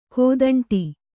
♪ kōdaṇṭ'i